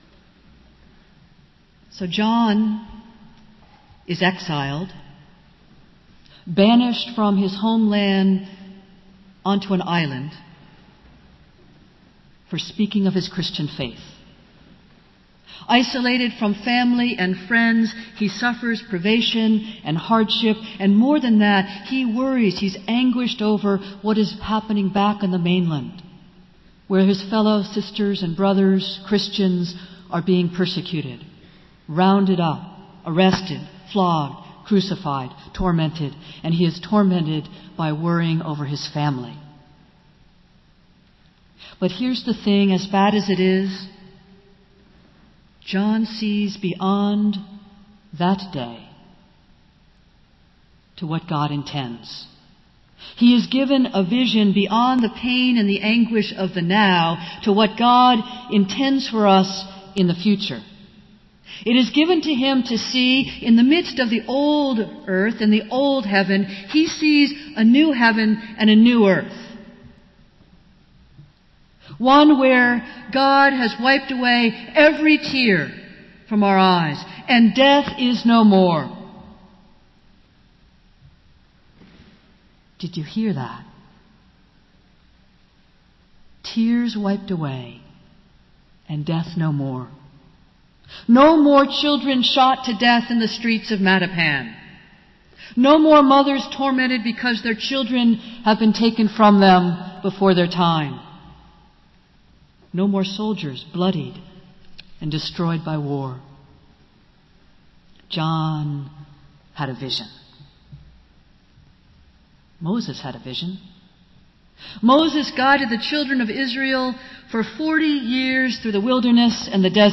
Festival Worship - World Communion Sunday